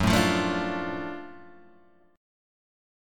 F#mM7#5 chord {2 5 3 2 3 2} chord